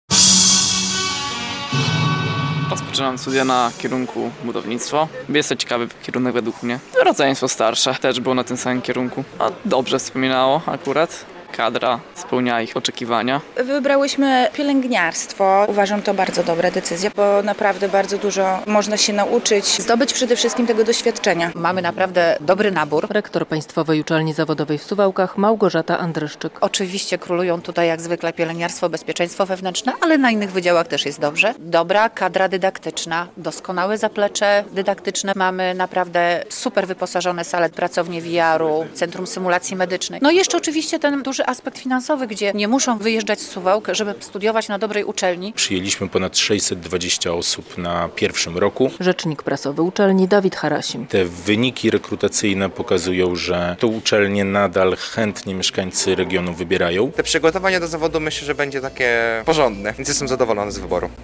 Inauguracja roku akademickiego w Państwowej Uczelni Zawodowej w Suwałkach, 7.10.2025, fot.